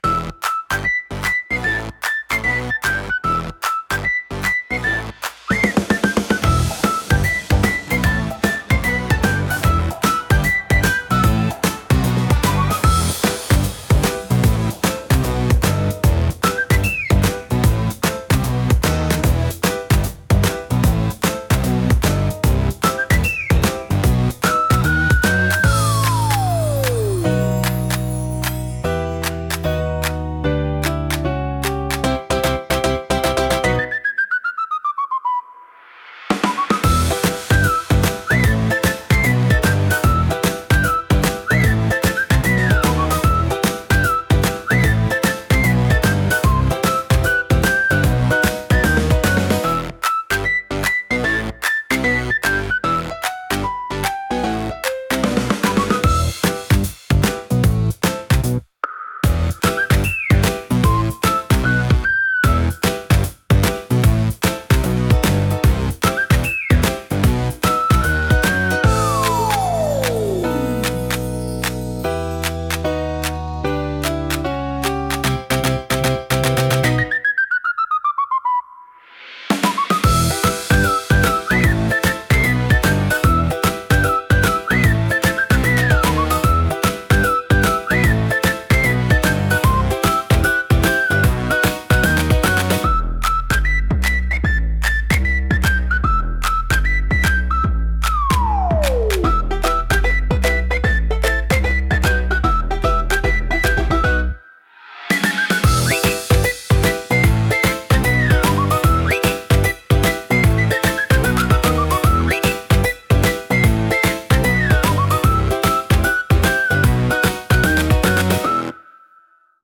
軽快なリズムと遊び心あふれるメロディが、聴く人に楽しさと自由なエネルギーを届けます。